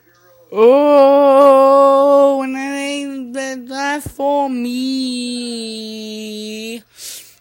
Ghost Noises